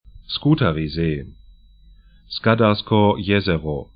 Skutarisee 'sku:tari-ze: Skadarsko jezero
'skadarskɔ: 'jɛzɛrɔ